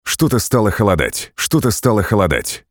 «Отрубите» всё , что ниже 40 гц , должно выпрямиться.
Срез ниже 40Гц Можно при желании достаточно симметрично выровнять, но надо точнее частоту среза подбирать.